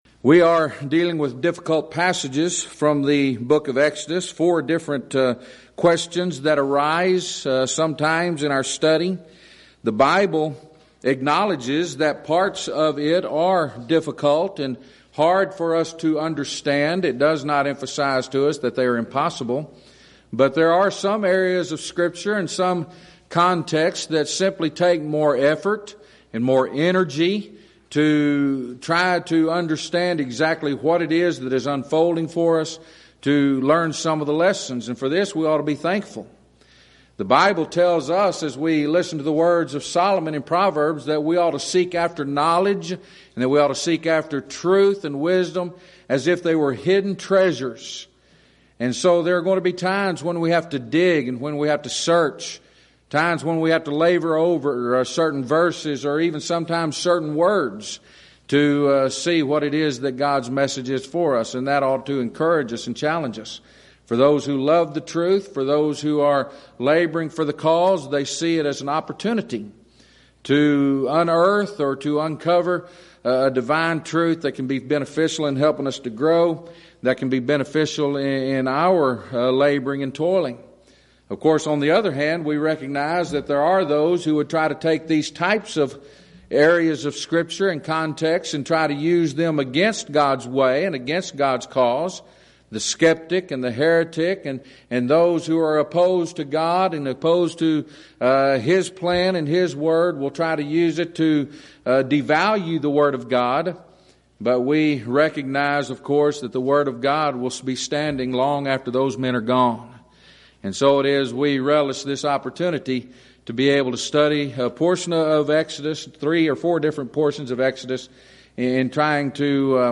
Event: 2nd Annual Schertz Lectures Theme/Title: Studies In Exodus